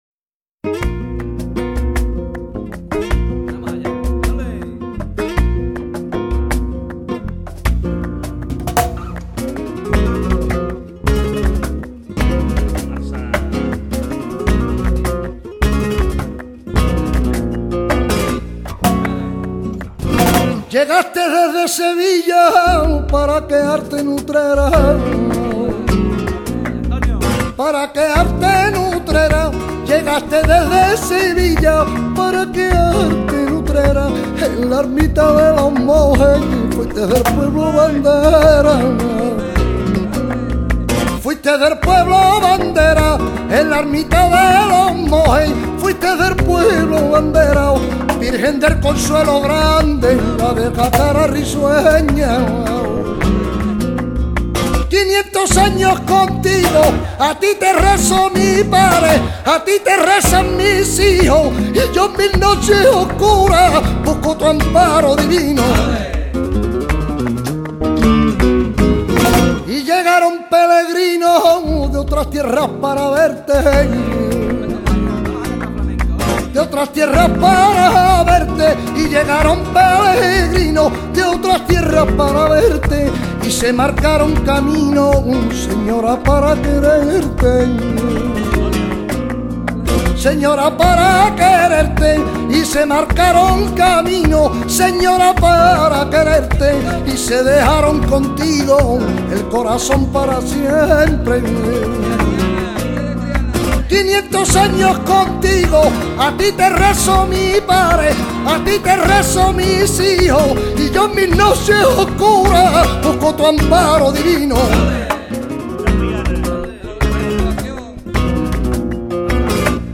Sevillanas